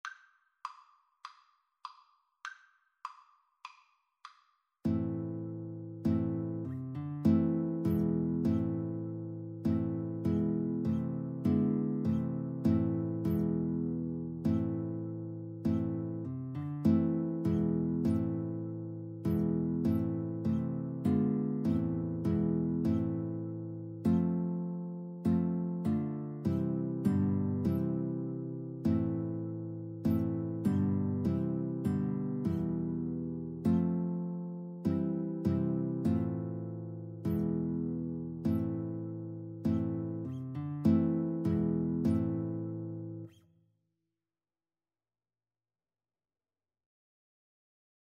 Classical Parry, Hubert Aberystwyth Alto Sax-Guitar Duet version
4/4 (View more 4/4 Music)
A minor (Sounding Pitch) (View more A minor Music for Alto Sax-Guitar Duet )
Moderato
Classical (View more Classical Alto Sax-Guitar Duet Music)